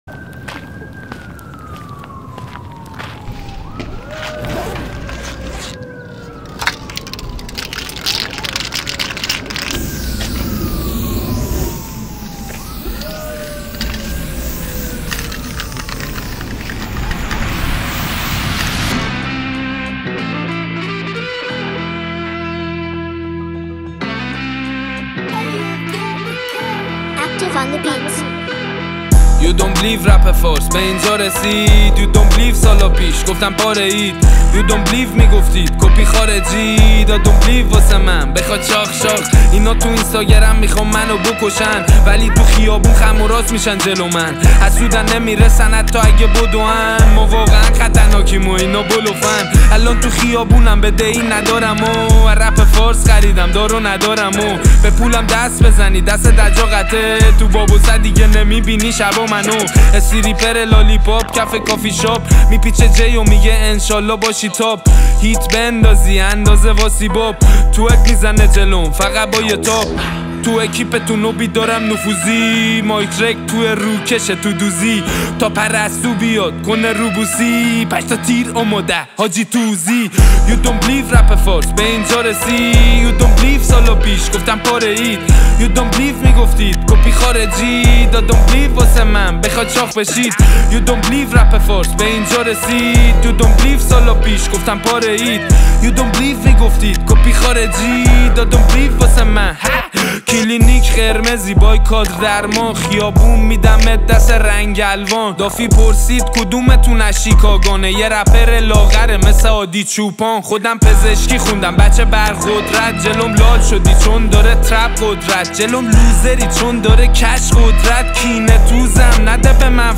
رپ فارس